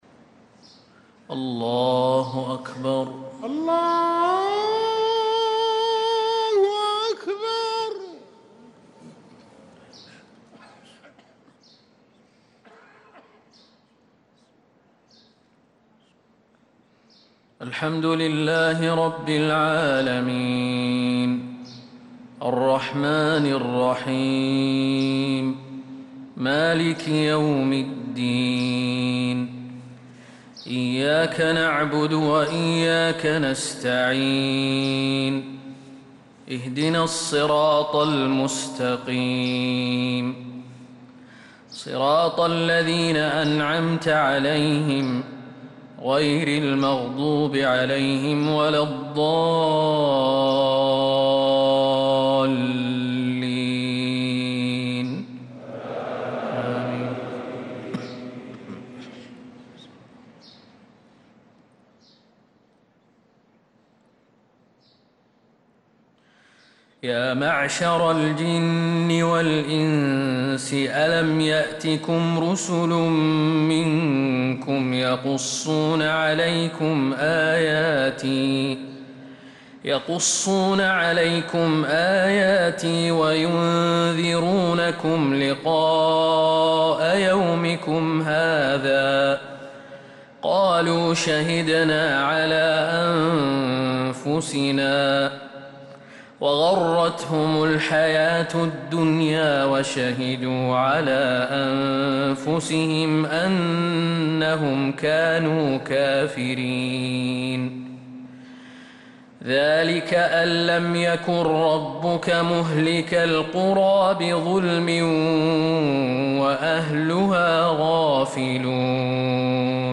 صلاة الفجر للقارئ خالد المهنا 4 ربيع الأول 1446 هـ